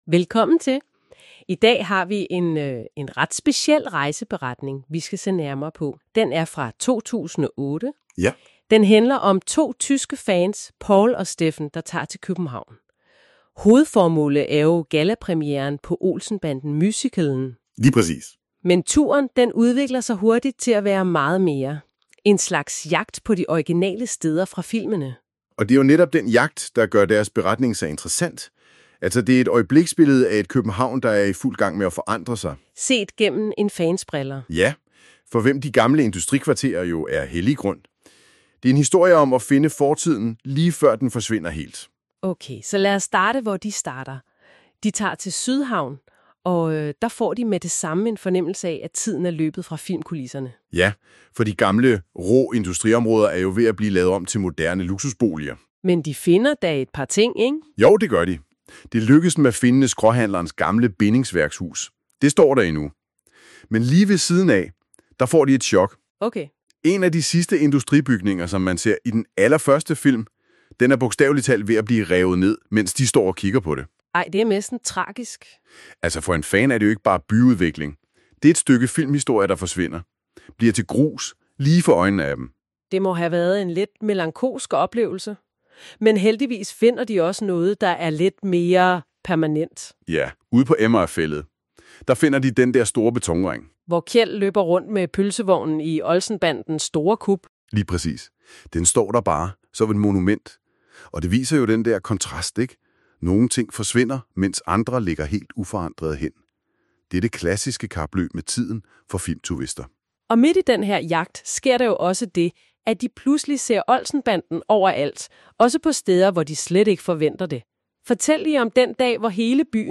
Lydresumé i podcastformat
MP3 (AI-genereret lydindhold)